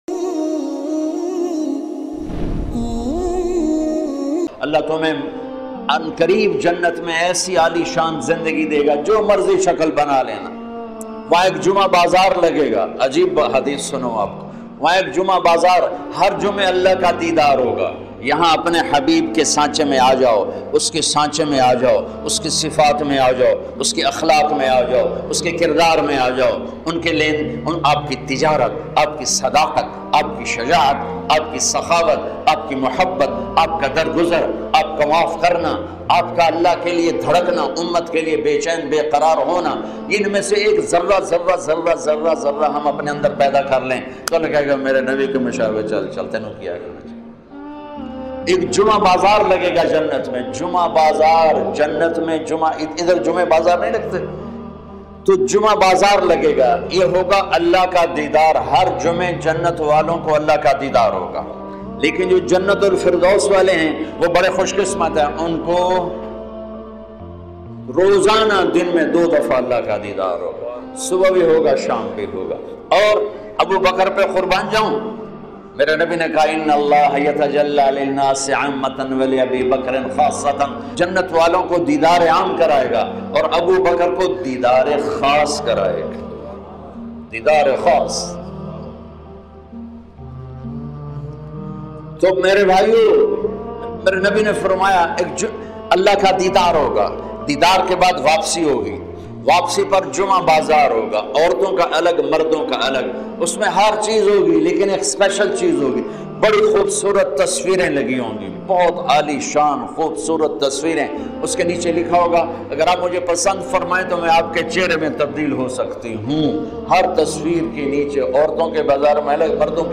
Jannat Mein Juma Bazar Molana Tariq Jamil Special Bayan MP3 Download